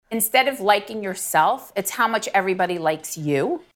That’s one mom who recently talked about social media’s impact on her kids on the TODAY show.